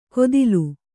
♪ kodilu